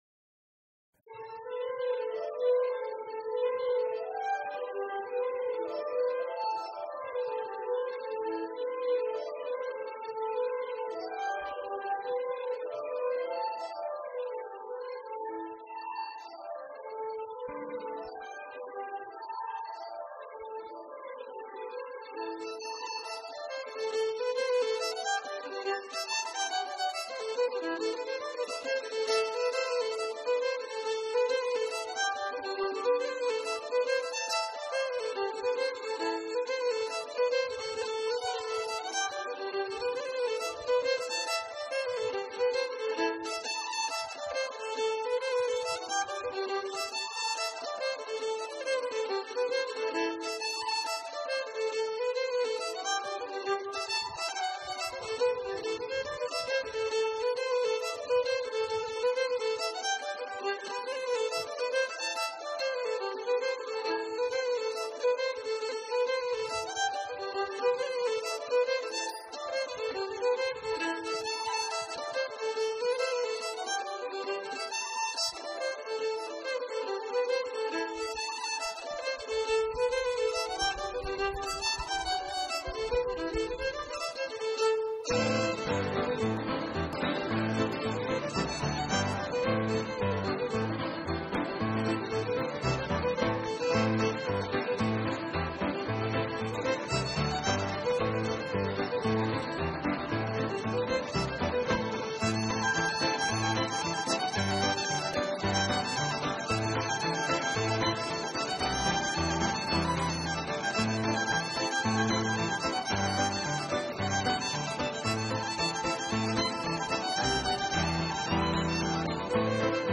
jig et reel connus en Irlande par une formation bretonne
Pièce musicale éditée